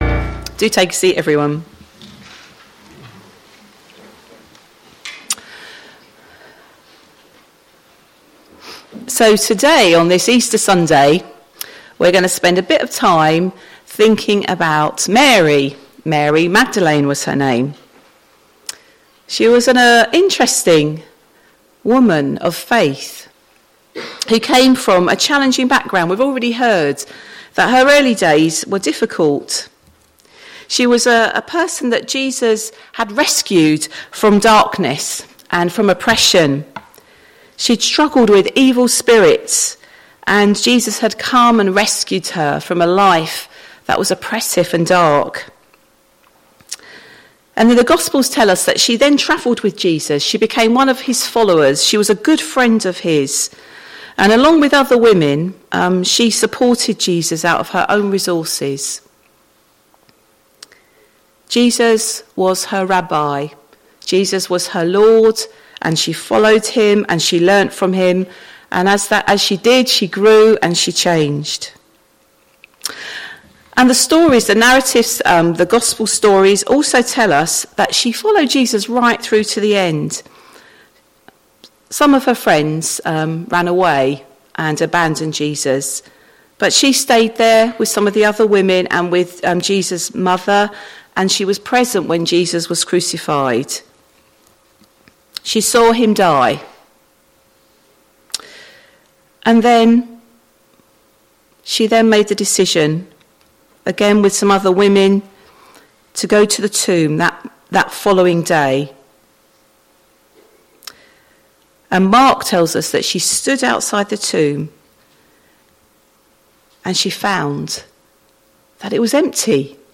Easter Sunday Service